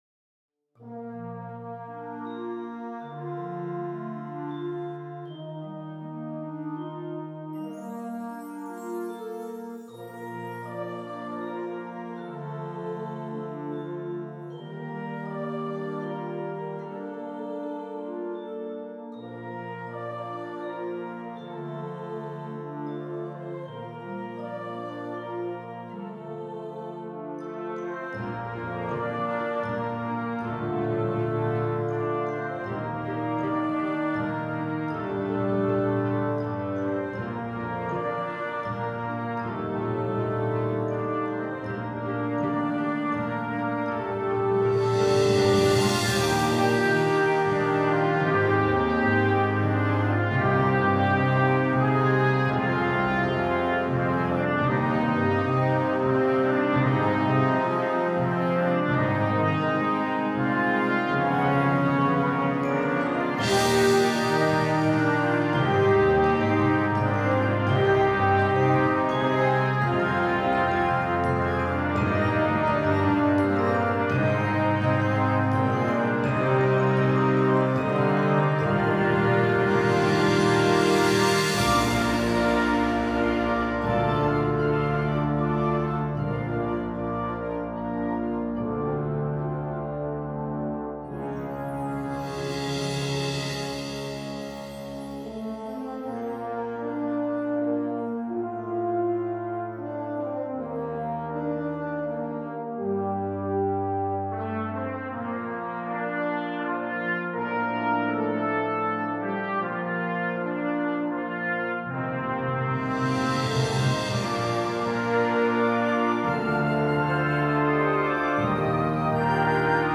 Concert Band
MIDI Demo